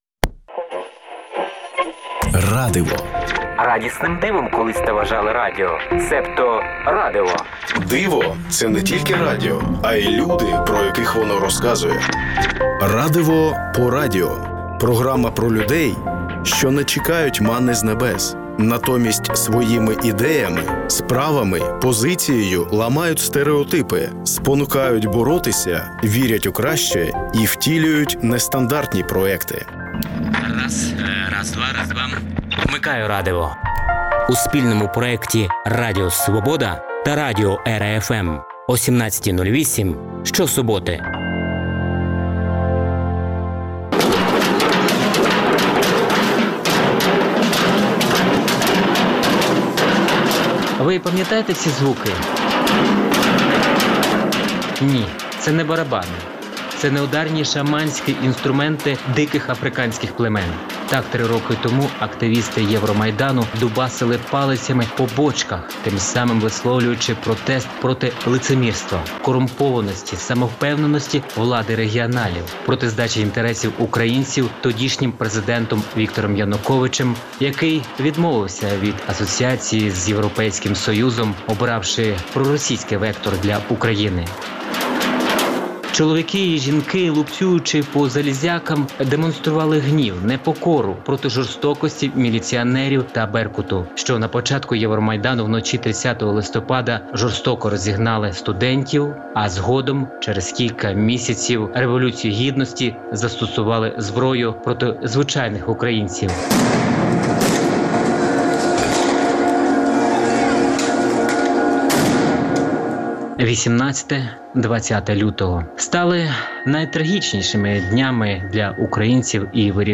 В передачі звучатимуть інтерв’ю, портретні та радіозамальовки про волонтерів, військових, вчителів, медиків, громадських активістів, переселенців, людей з особливими потребами, які живуть в різних містах України, тощо.